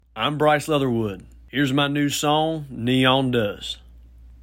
LINER Bryce Leatherwood (Neon Does) 2
LINER-Bryce-Leatherwood-Neon-Does-2.mp3